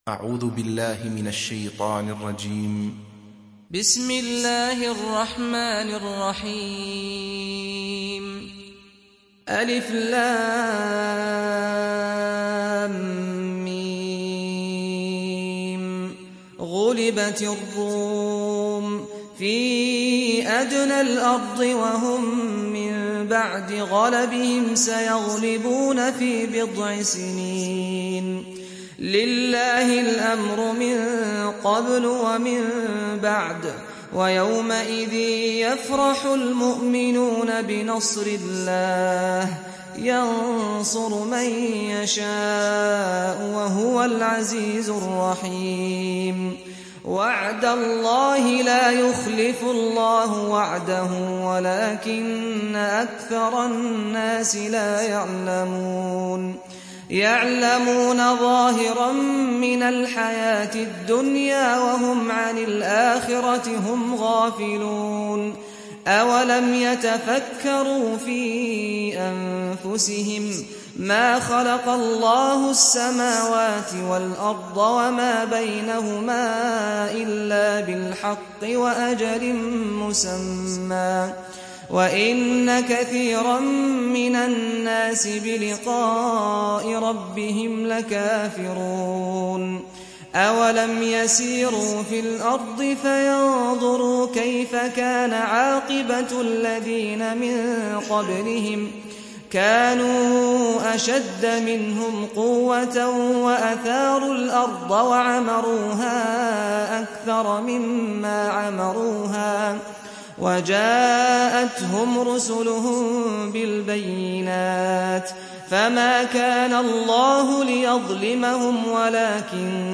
Surah Ar Rum Download mp3 Saad Al-Ghamdi Riwayat Hafs from Asim, Download Quran and listen mp3 full direct links